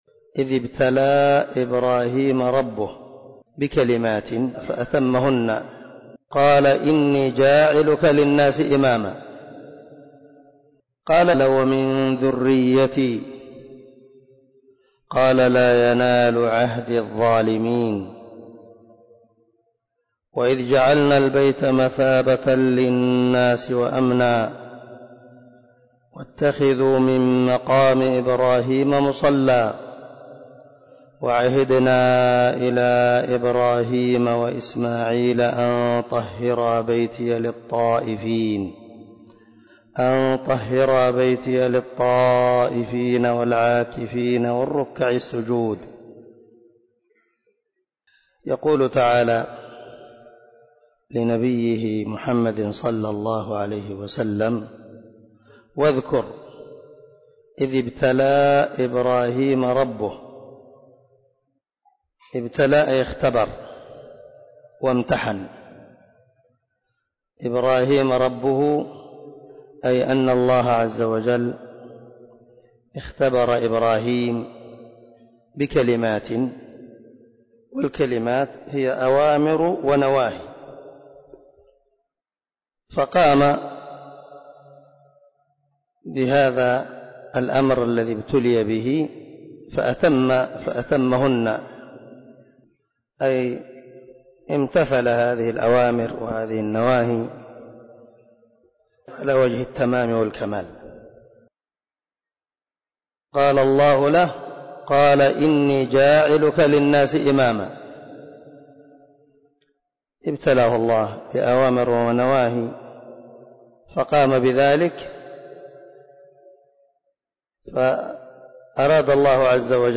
052الدرس 42 تفسير آية ( 124 – 125 ) من سورة البقرة من تفسير القران الكريم مع قراءة لتفسير السعدي